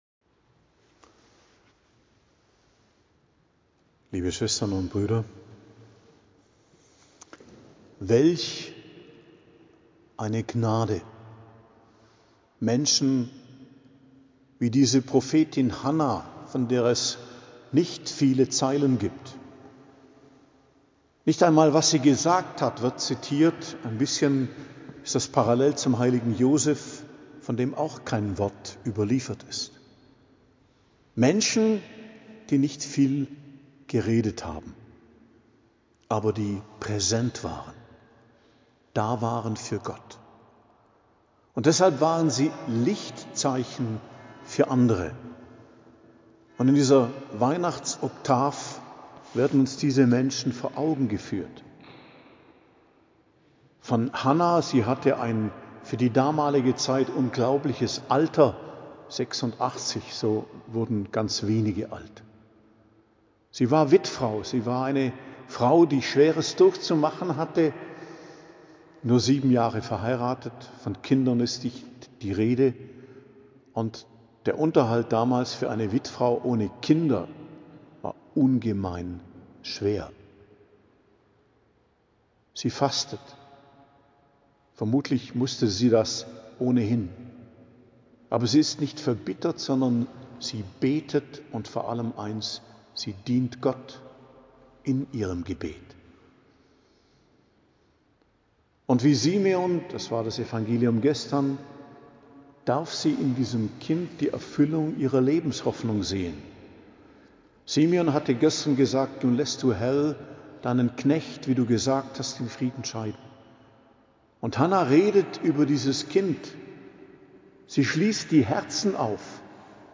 Predigt am 6. Tag der Weihnachtsoktav, Dienstag, 30.12.2025